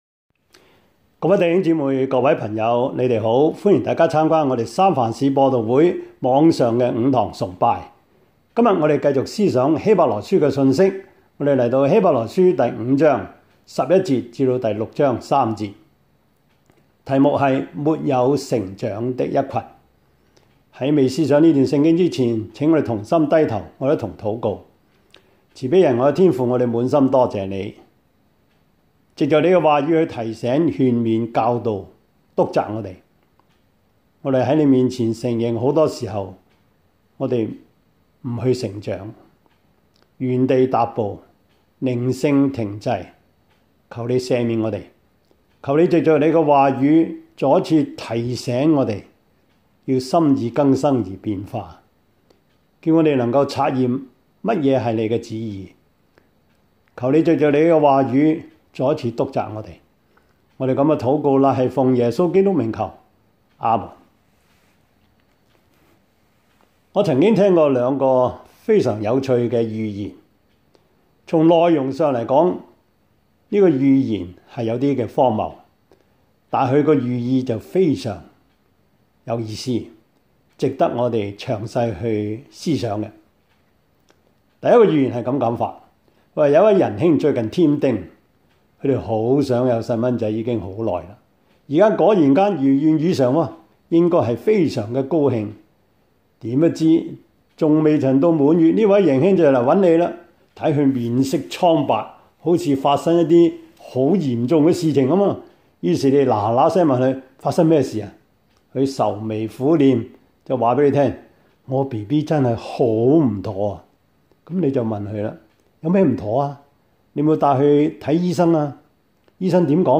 Service Type: 主日崇拜
Topics: 主日證道 « 認識神 第一課: 中國的原始宗教 »